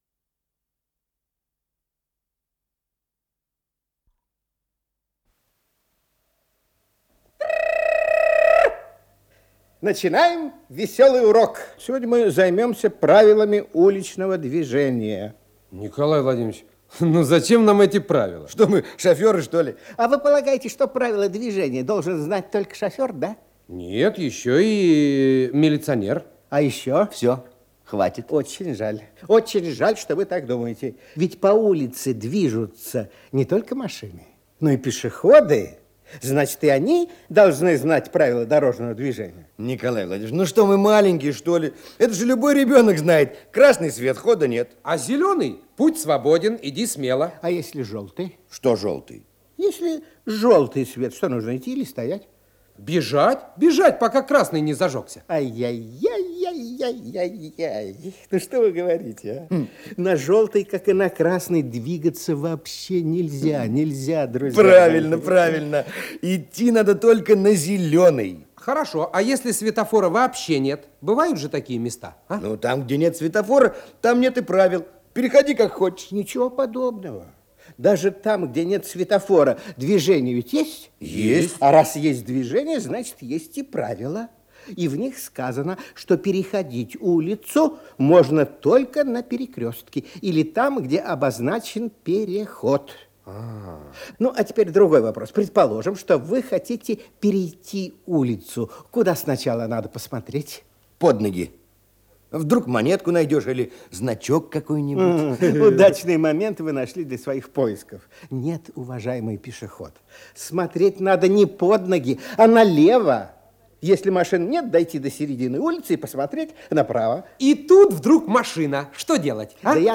с профессиональной магнитной ленты
ПодзаголовокПравила дорожного движения, интермедия
ИсполнителиНиколай Литвинов
Скорость ленты38 см/с